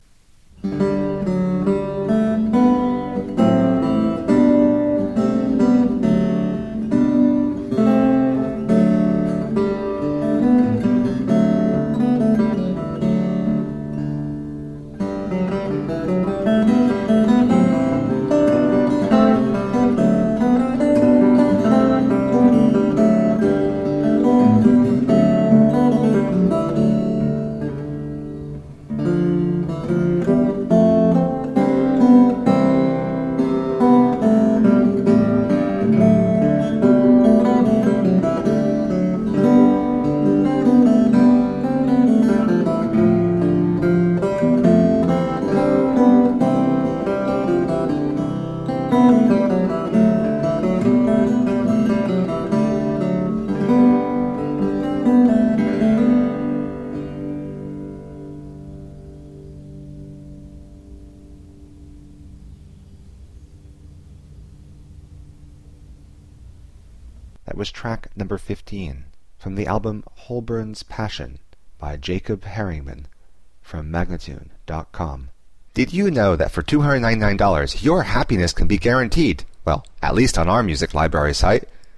Renaissance lute.
Bandora
Classical, Renaissance, Instrumental